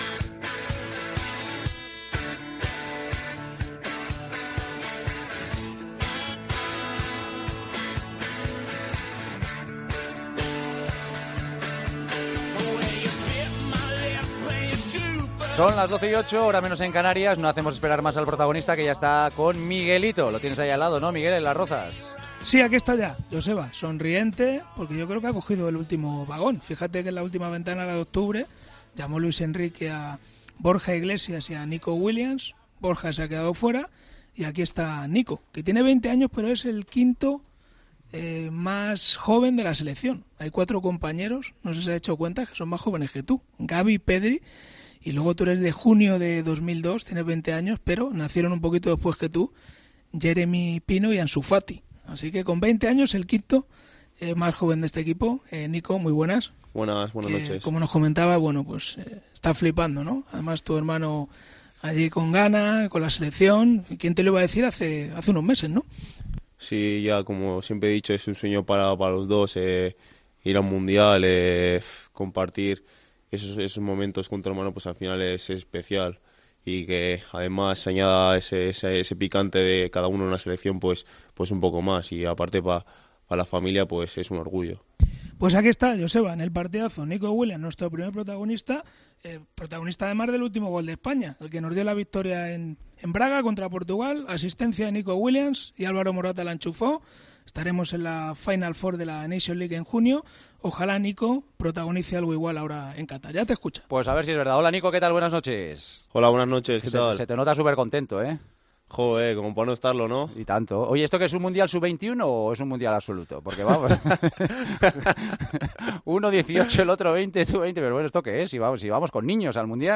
AUDIO - ENTREVISTA A NICO WILLIAMS, EN EL PARTIDAZO DE COPE